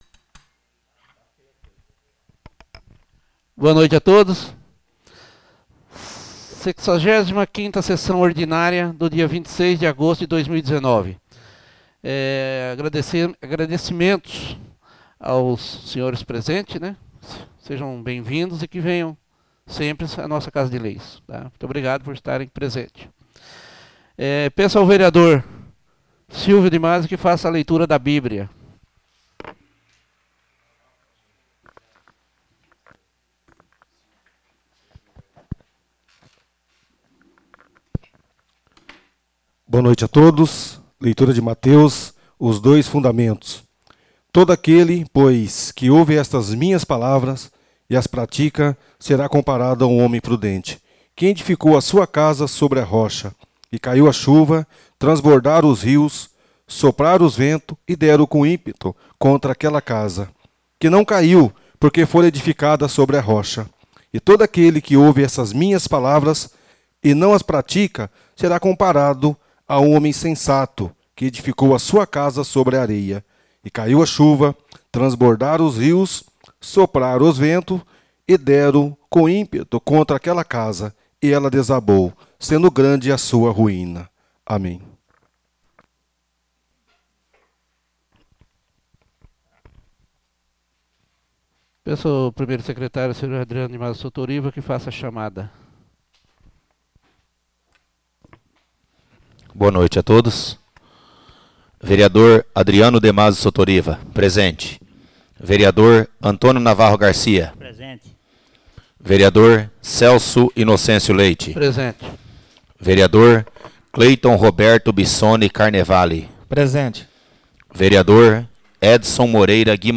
Áudio da Sessão Ordinária 65/2019. — Câmara Municipal